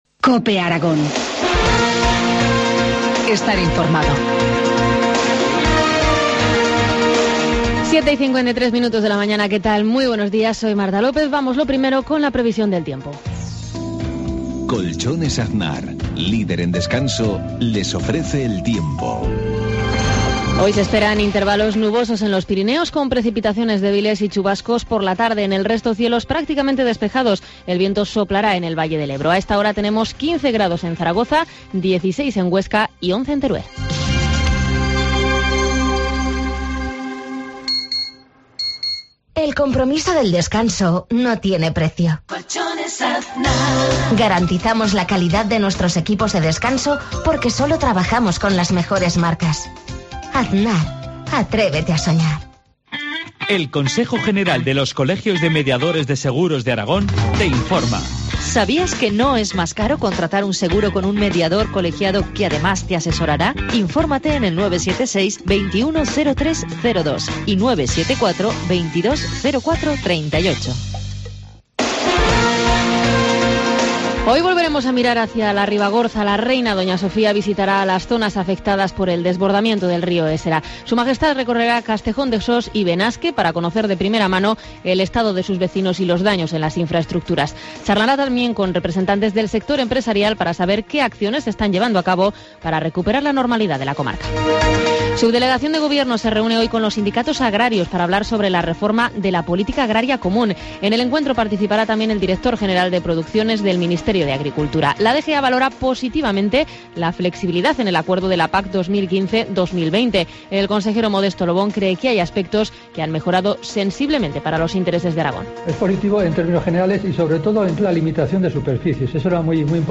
Informativo matinal, viernes 28 de junio, 7.53 horas
Informativo matinal